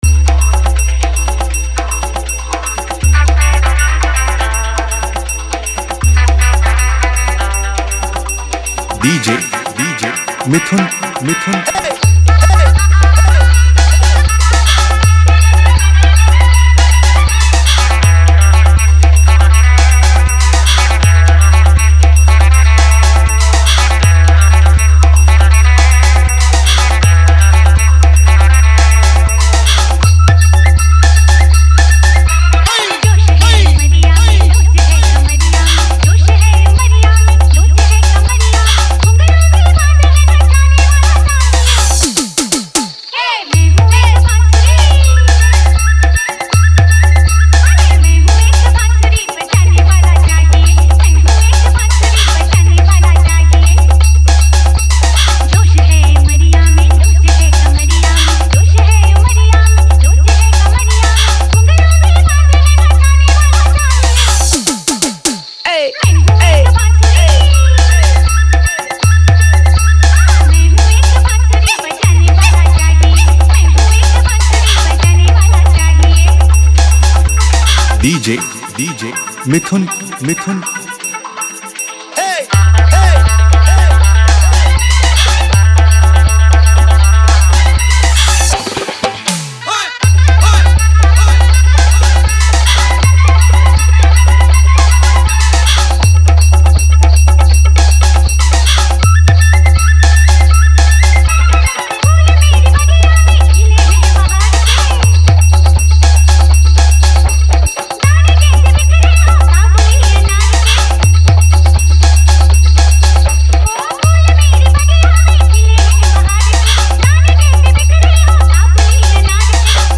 Hindi New Style Dance Dhamaka Mix